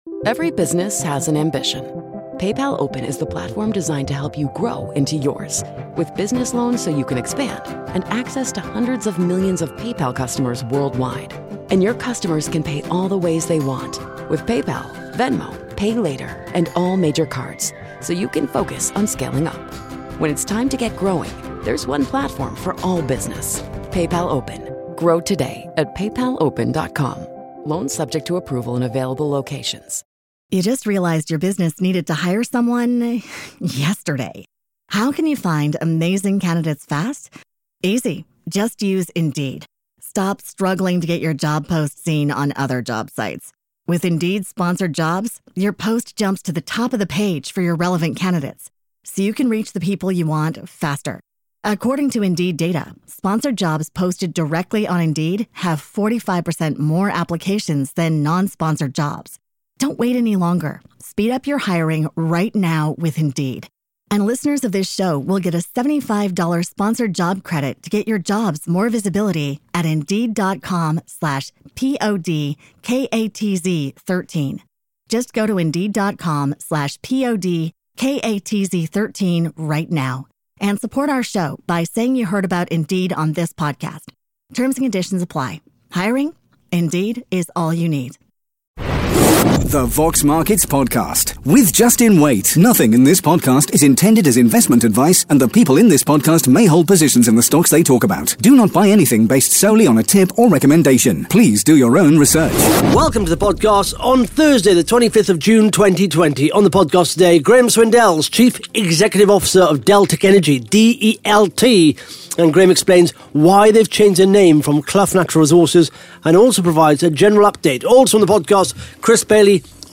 (Interview starts at 7 minutes 56 seconds)